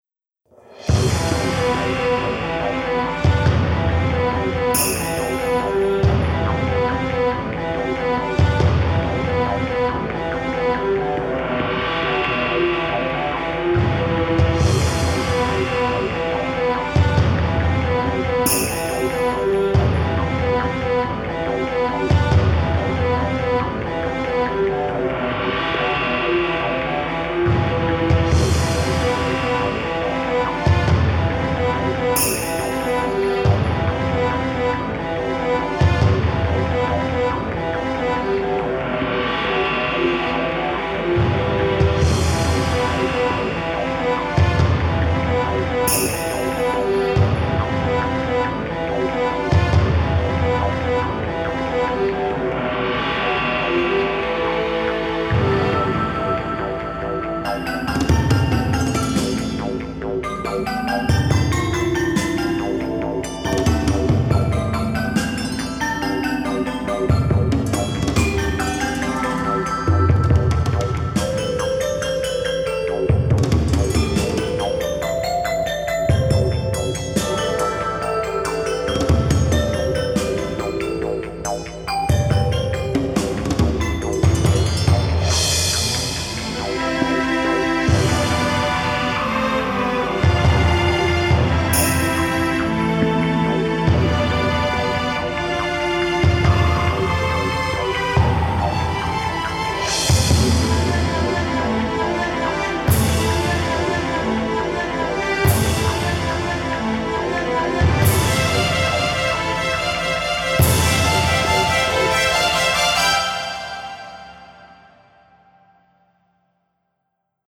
オーケストラ風のサウンドと、エスニックな楽器を使用したダークなイメージの楽曲です。